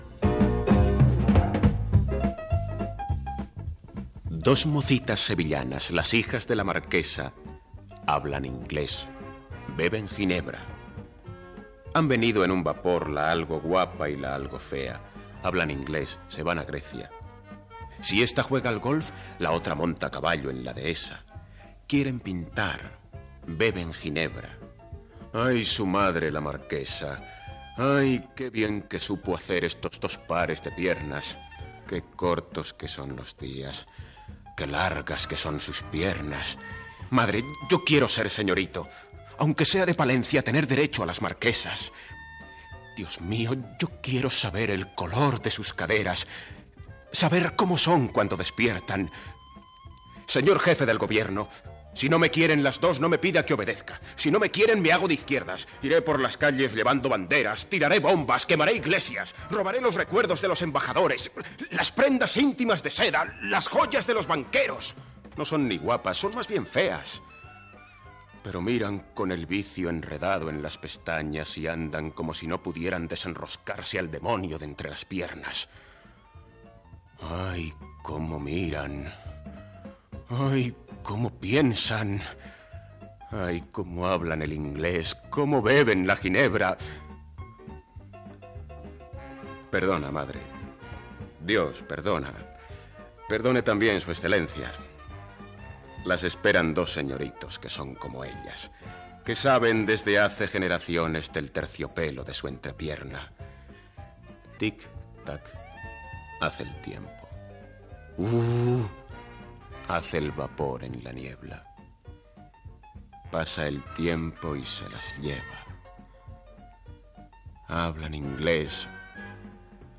Recitat de la poesia "Las hijas de la marquesa" de Fernando Fernan Gómez